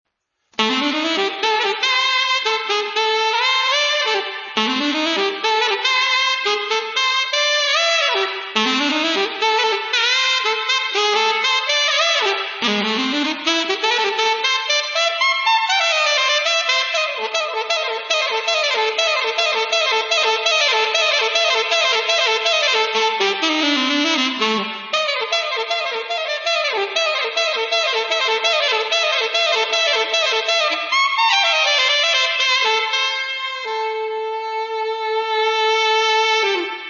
标签： 120 bpm Dance Loops Woodwind Loops 2.26 MB wav Key : Unknown
声道立体声